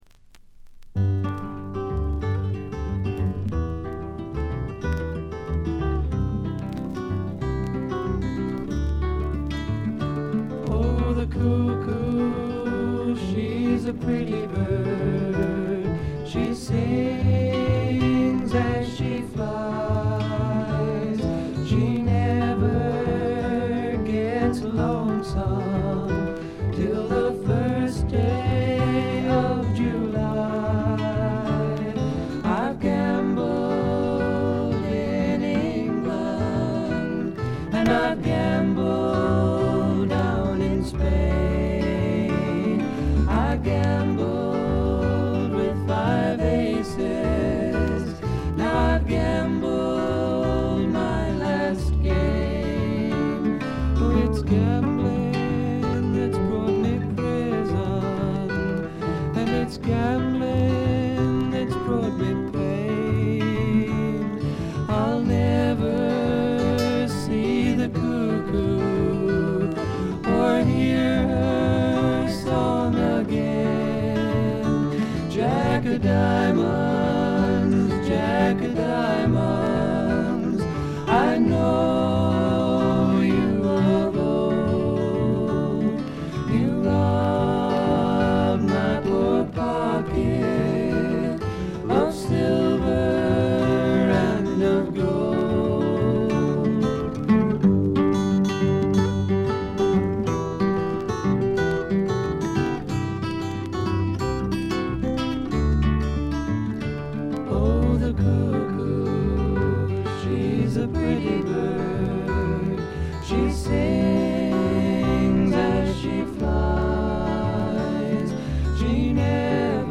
低いバックグラウンドノイズは常時出ていますが、特に目立つノイズはありません。
試聴曲は現品からの取り込み音源です。